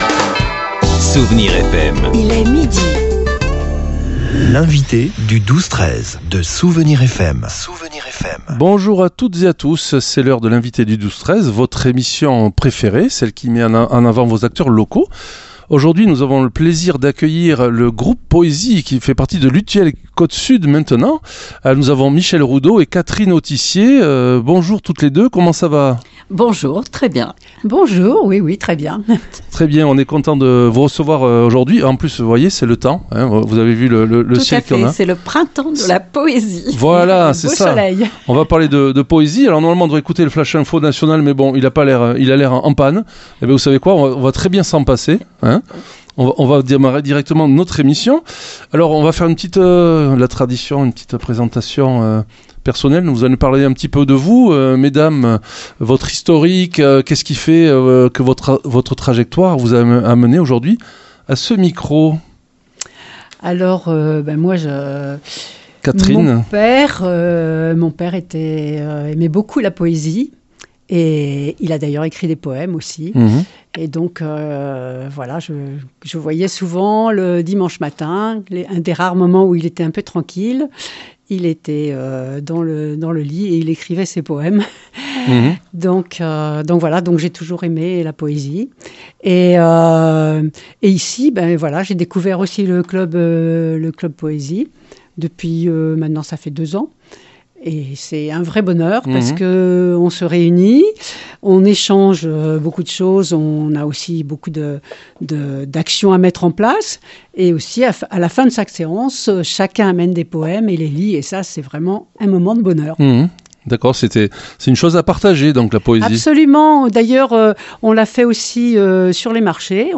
Vous pourrez savourer la lecture de quelques une des oeuvres issue de notre territoire !